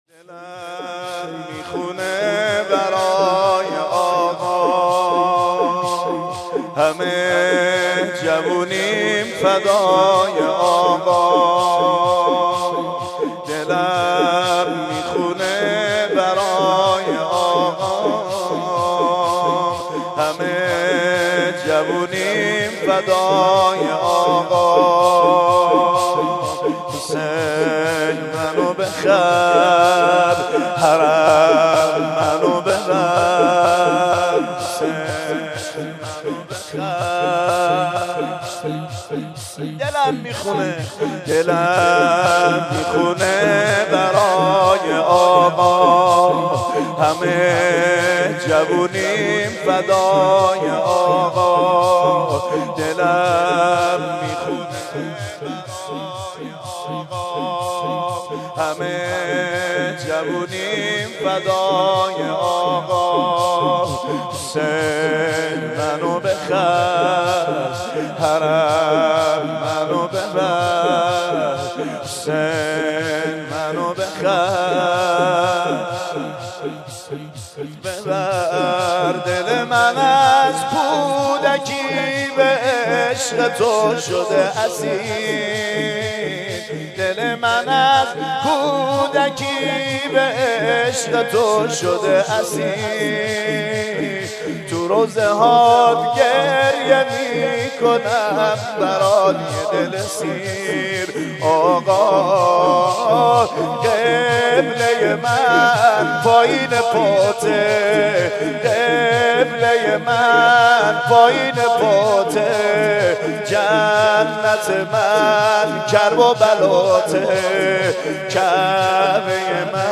با مداحی حاج سعید حدادیان برگزار شد
مداحی